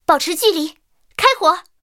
I号开火语音2.OGG